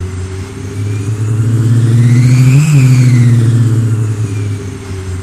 Turbine On and Climax